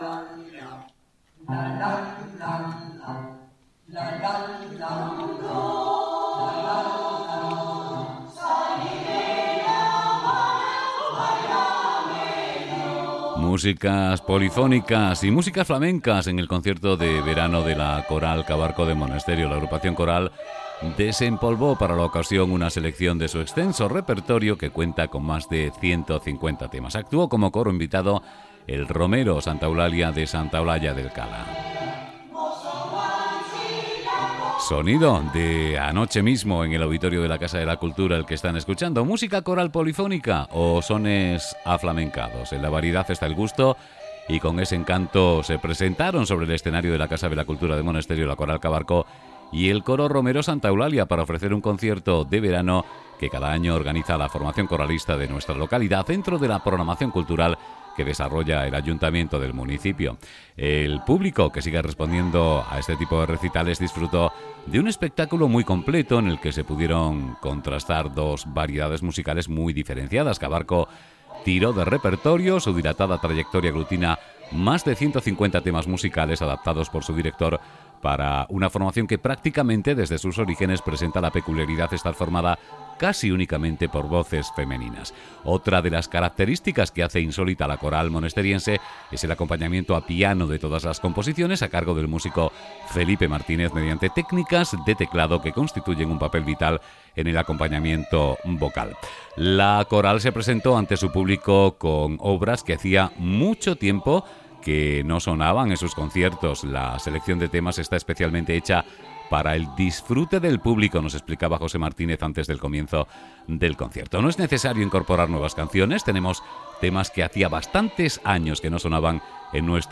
Música polifónica y flamenca en el concierto de verano de la coral Cabarco en Monesterio